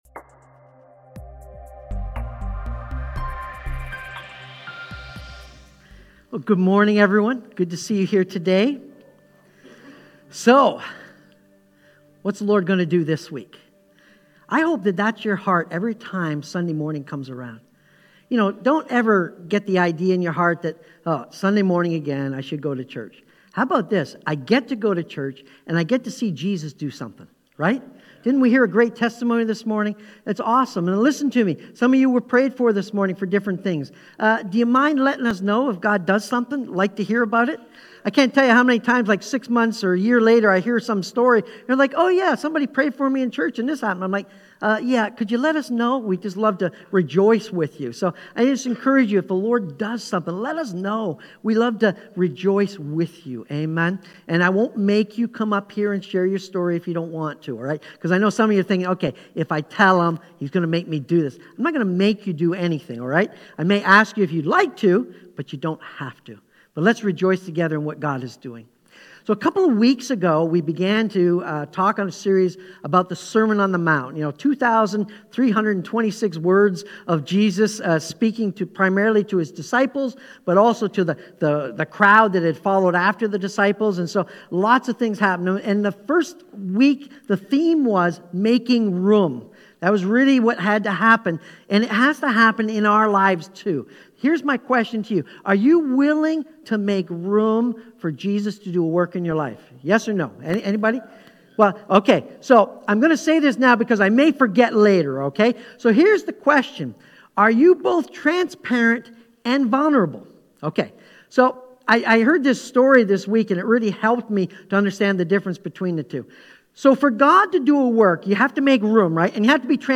Sermon-On-The-Mount-Its-All-About-Your-Commitments.mp3